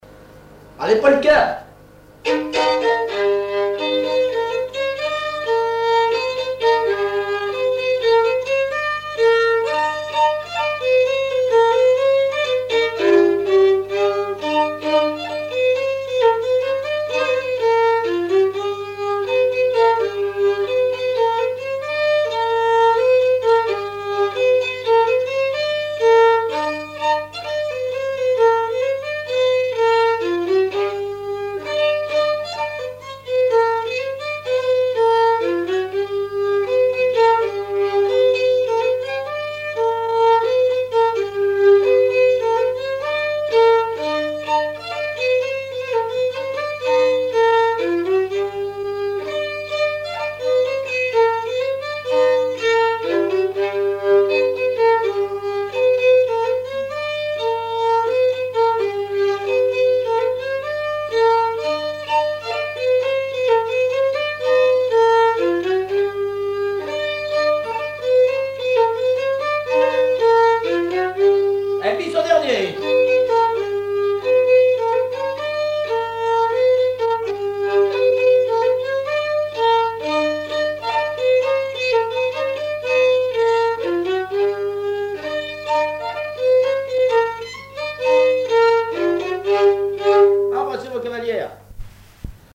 danse : polka
Auto-enregistrement
Pièce musicale inédite